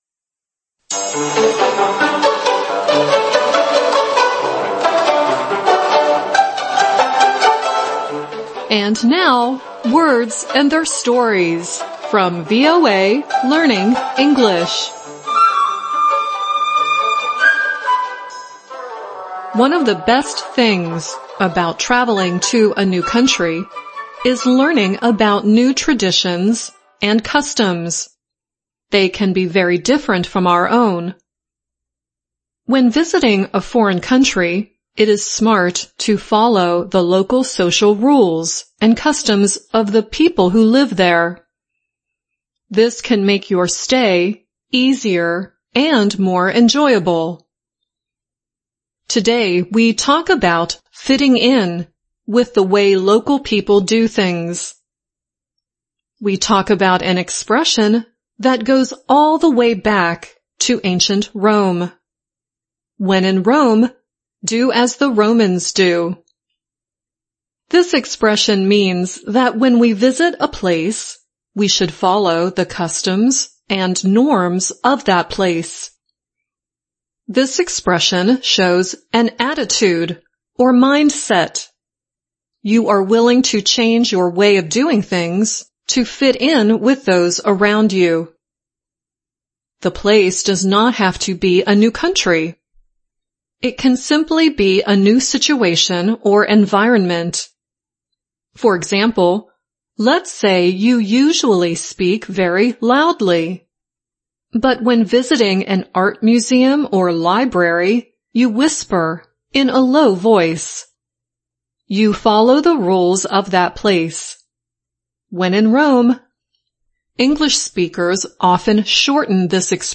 VOA慢速英语逐行复读精听提高英语听力水平